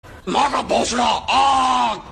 ma ka bhosda aag Meme Sound Effect